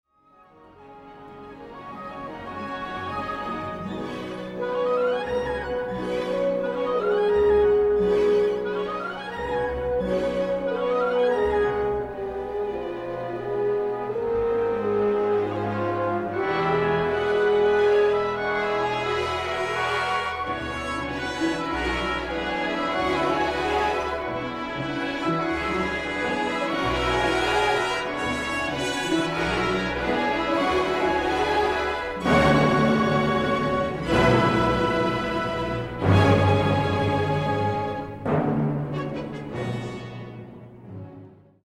Pierwsza część rozpoczyna się bardzo powoli, Reiner z pietyzmem buduje nastrój. Jednak przejście do szybszej w założeniu sekcji jest statyczne. Wszystko jest bardzo przejrzyste, słychać tu mnóstwo detali, ale to jednak brzmi to morze zaskakująco… sucho.
Fritz Reiner, Chicago Symphony Orchestra, 1960, 25:00, RCA Victor